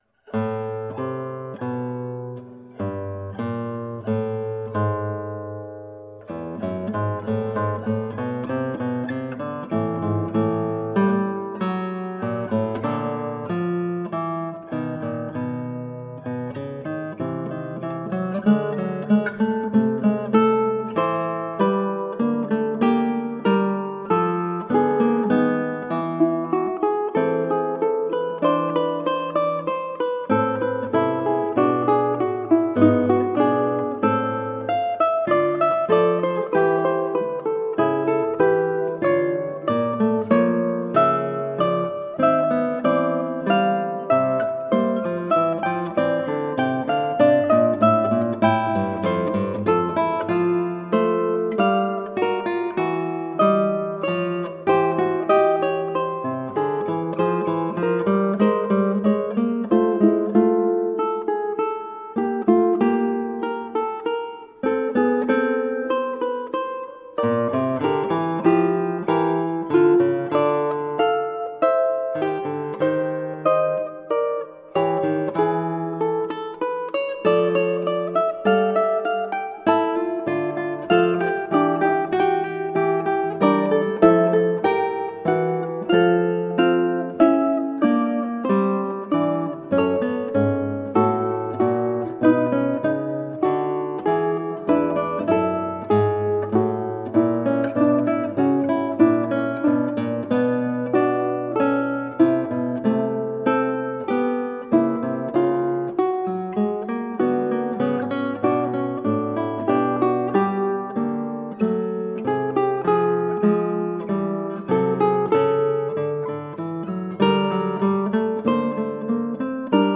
Colorful classical guitar.
slowly reveals an appropriately pensive fugue.